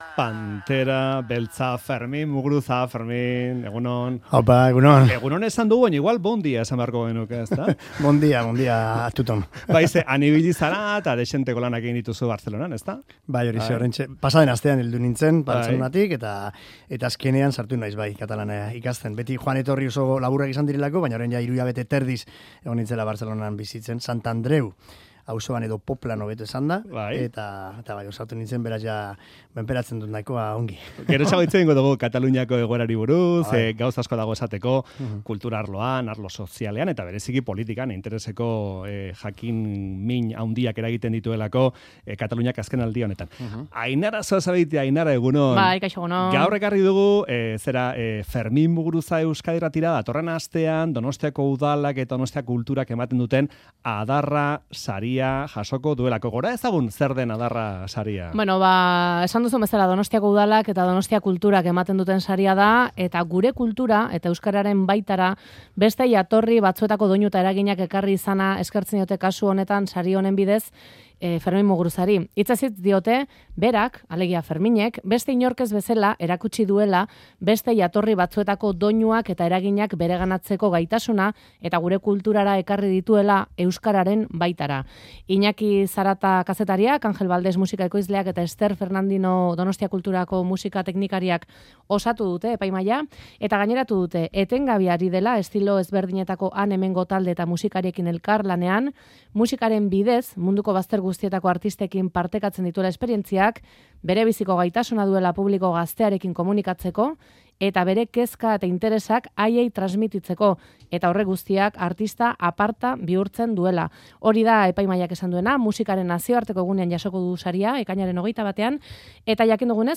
Fermin Muguruzari elkarrizketa Adarra saria jaso aurretik
Saria jaso bezperatan 'Faktoria'n izan dugu eta harekin mintzatu gara luze eta zabal.